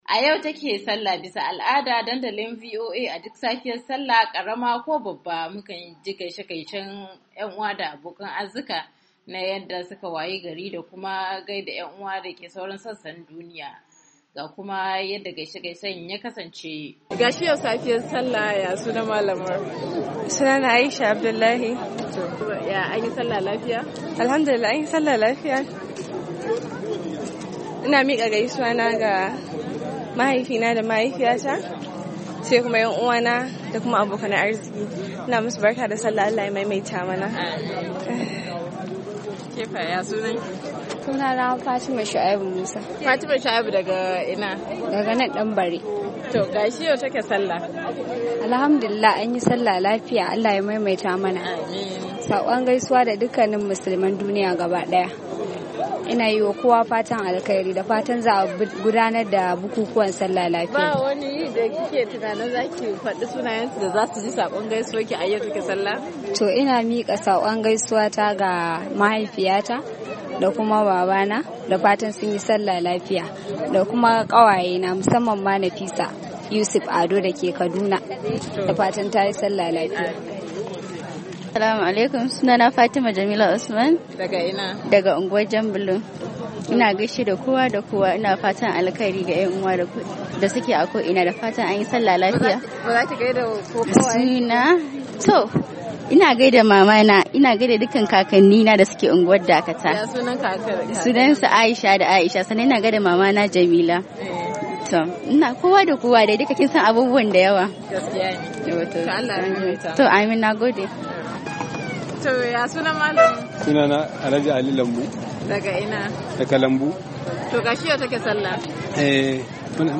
Yau ranar Sallah karama, jama'a suna nuna murnar su, da kuma gaishe gaishen su zuwa yan uwa da abokan arziki.